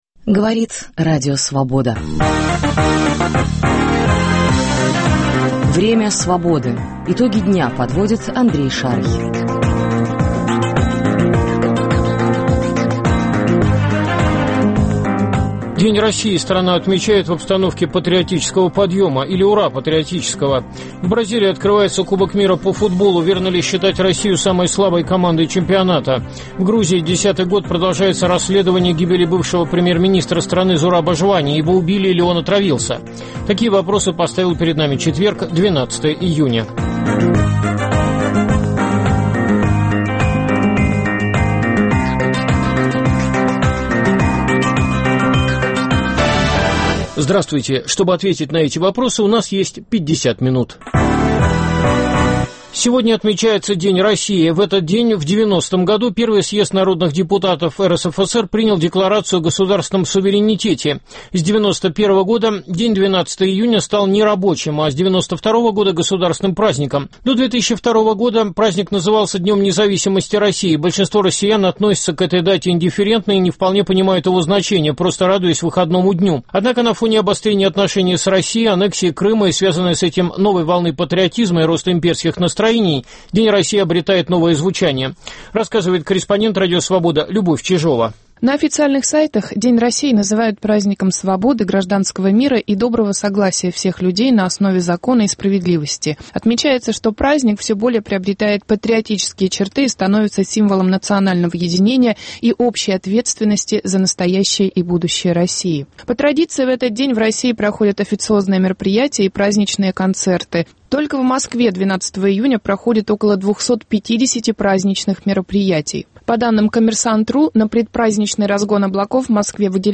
В эфире РС - крмыско-татарский политик Мустафа Джемилев, социолог Лев Гудков, футбольный эксперт Александр Бубнов.